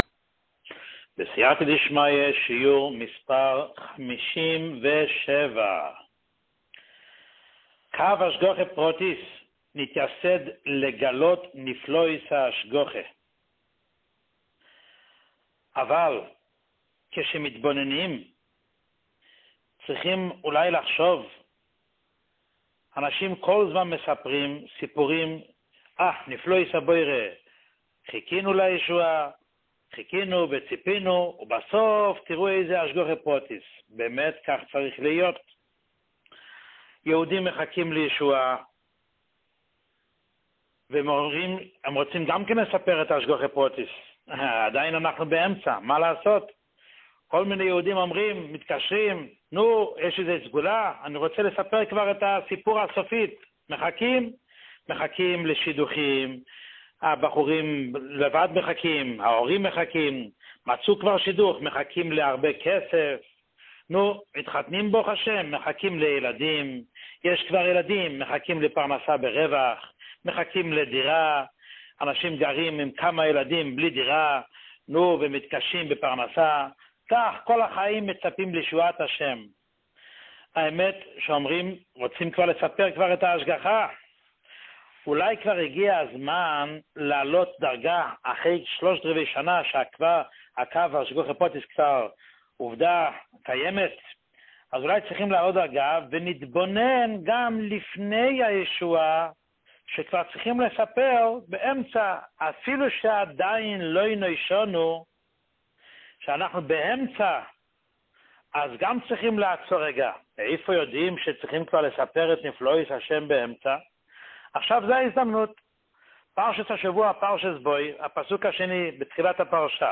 שיעורים מיוחדים
שיעור 57